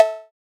Perc (Bootleg).wav